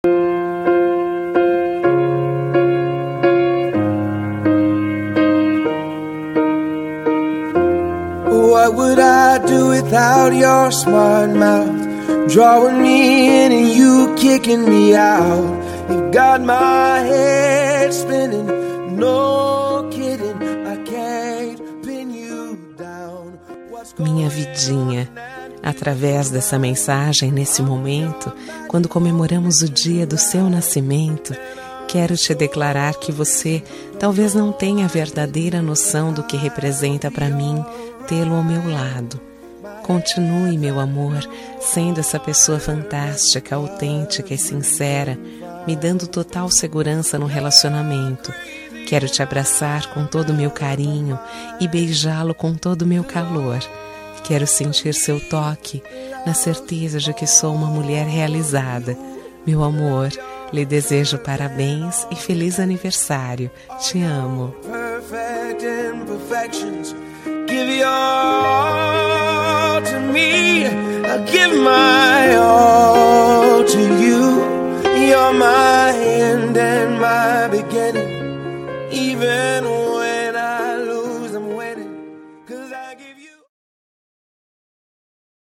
Aniversário Romântico – Voz Feminina – Cód: 350324